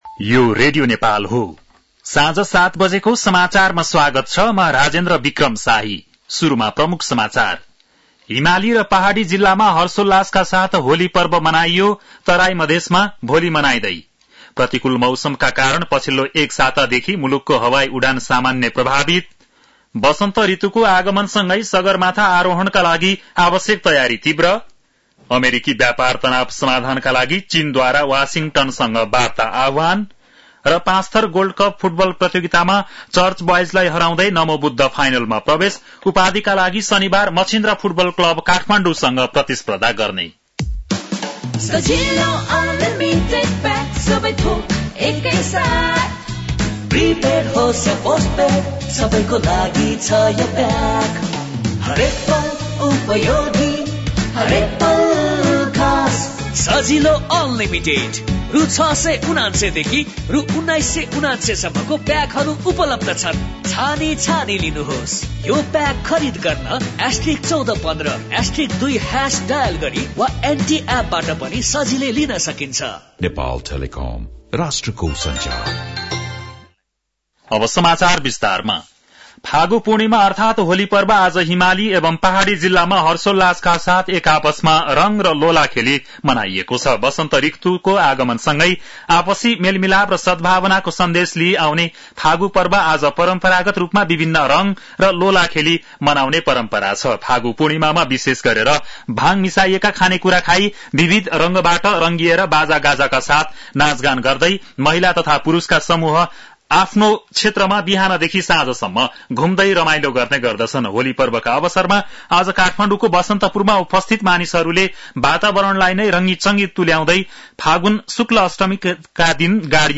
बेलुकी ७ बजेको नेपाली समाचार : ३० फागुन , २०८१